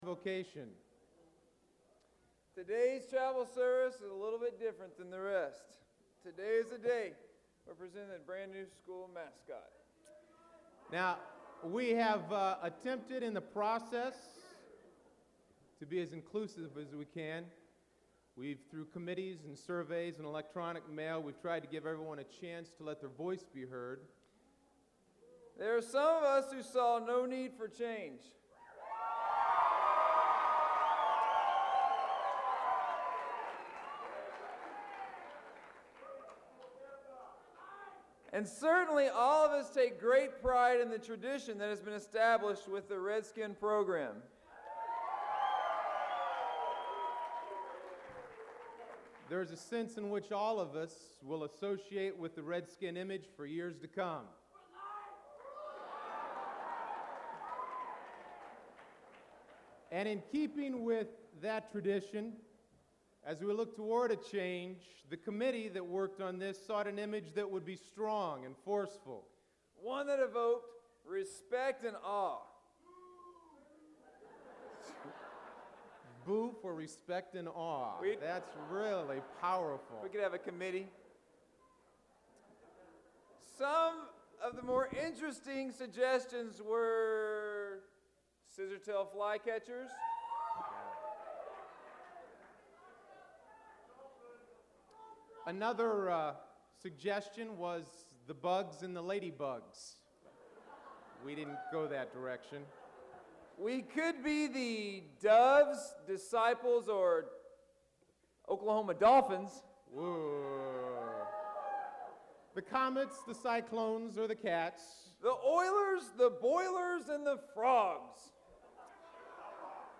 Southern Nazarene University Chapel April 21, 1998 (Mascot Change Announcement)
This recording includes the announcement in chapel of the change of mascot for SNU from The Redskins to The Crimson Storm in 1998.